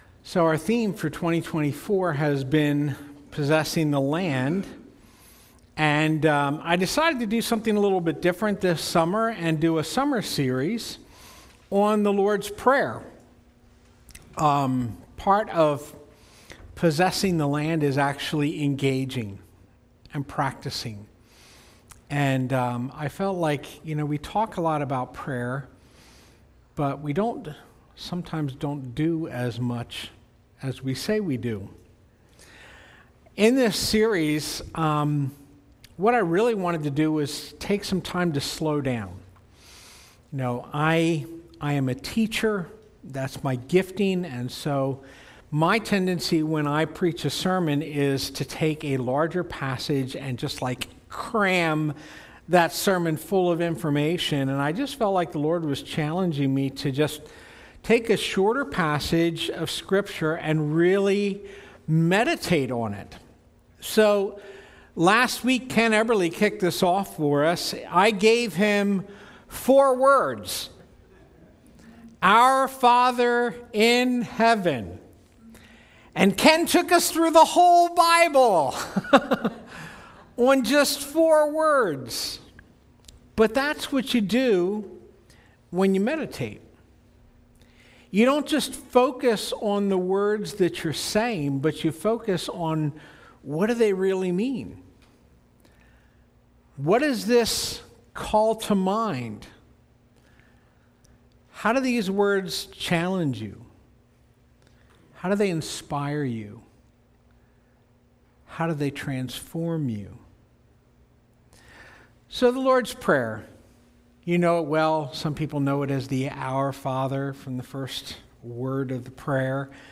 Sermons | Spring City Fellowship